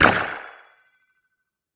Minecraft Version Minecraft Version latest Latest Release | Latest Snapshot latest / assets / minecraft / sounds / entity / glow_squid / hurt2.ogg Compare With Compare With Latest Release | Latest Snapshot
hurt2.ogg